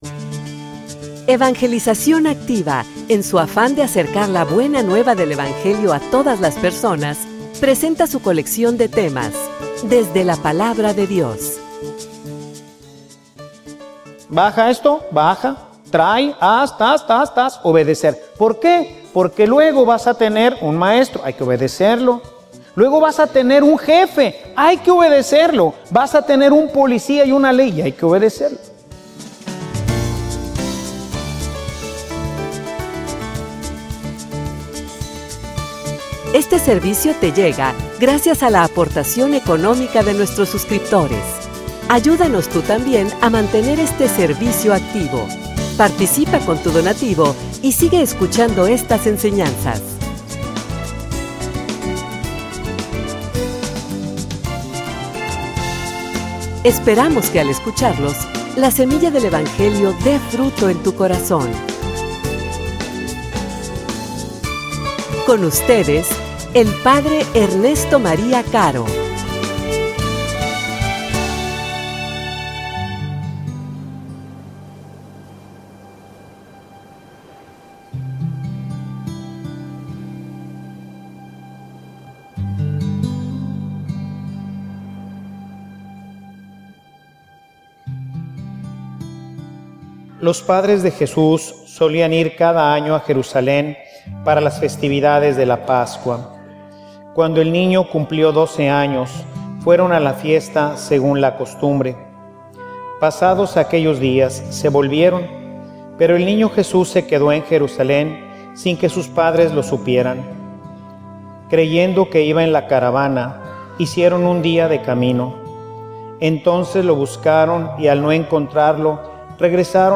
homilia_La_familia_en_el_proyecto_de_Dios.mp3